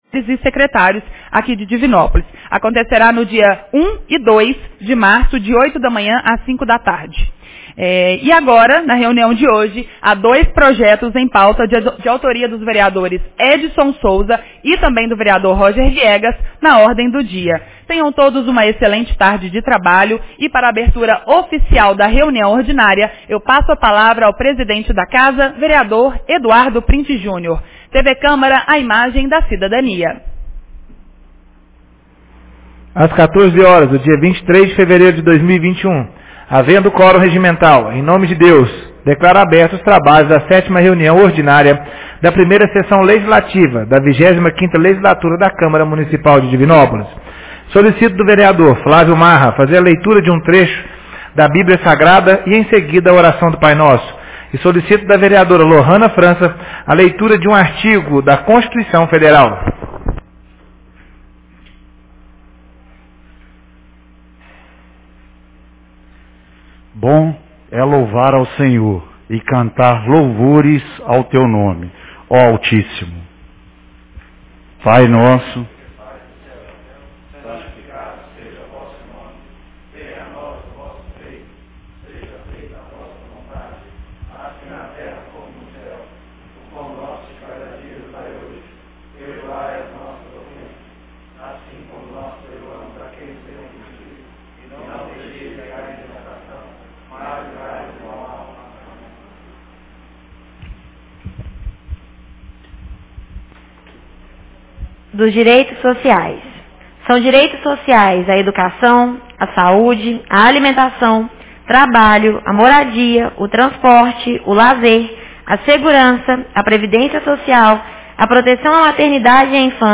Reunião Ordinária 07 de 23 fevereiro 2021